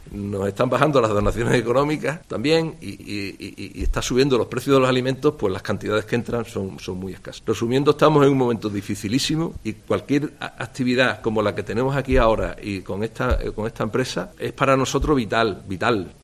en la rueda de prensa de presentación de la cata solidaria